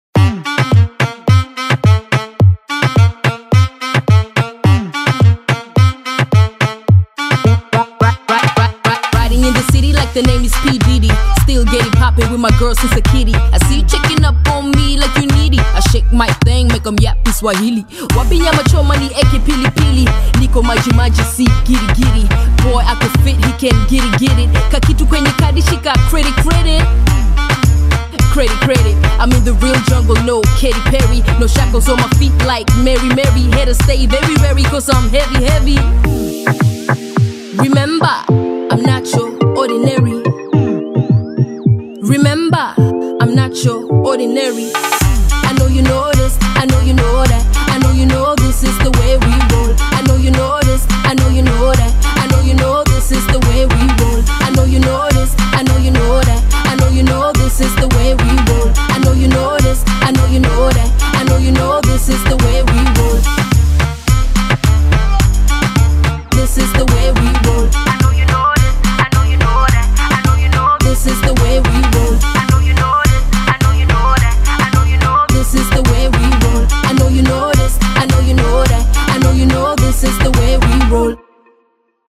BPM107